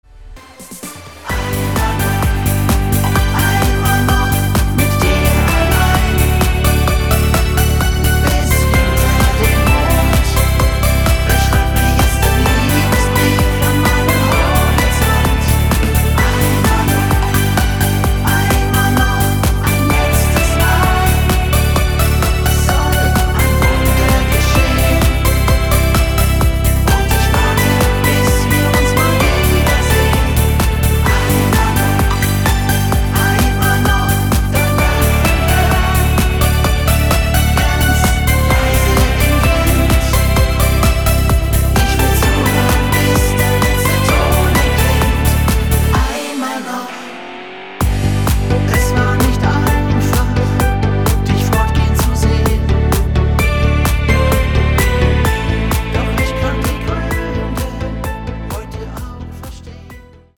der neue Discofoxhit